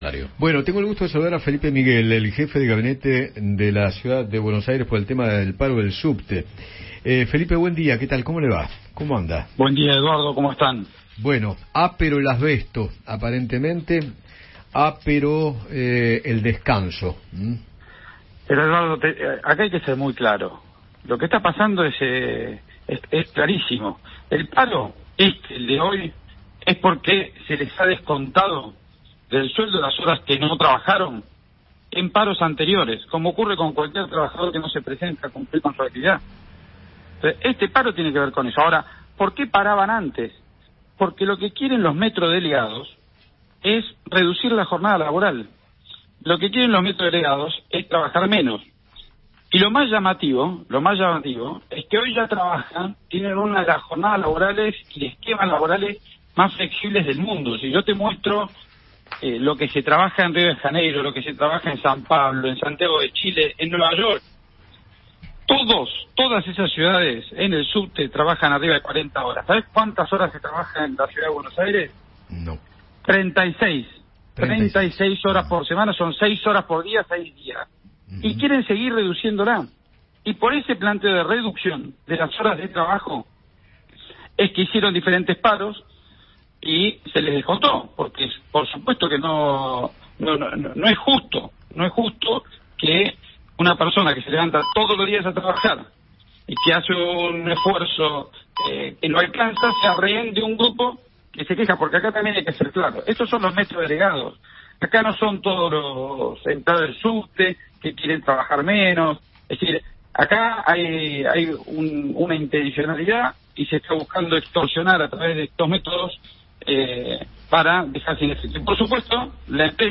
Felipe Miguel, jefe de Gabinete porteño, dialogó con Eduardo Feinmann sobre el paro total de los trabajadores del subte.